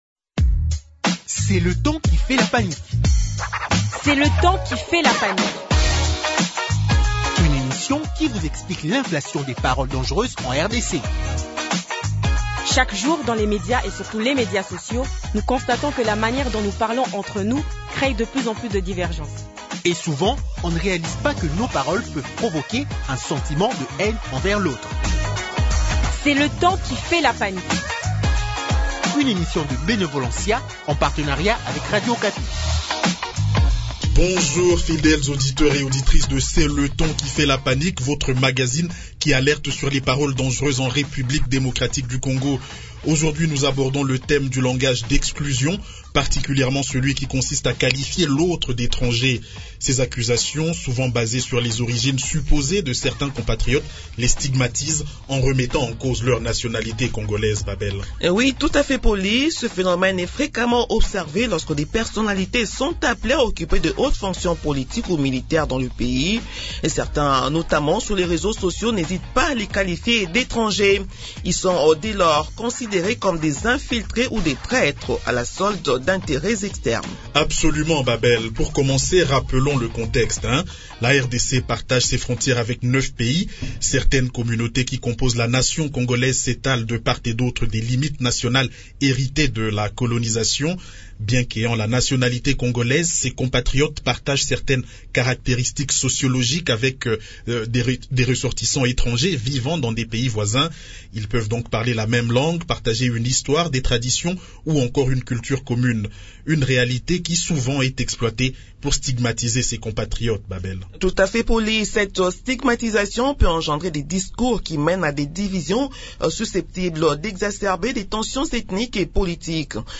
Pour en parler, deux invités : -